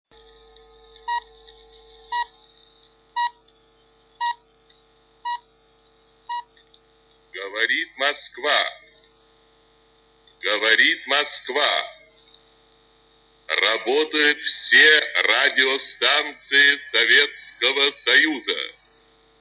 На этой странице собраны архивные записи голоса Юрия Левитана — символа эпохи.
Качество звука восстановлено, чтобы передать мощь и тембр легендарного диктора.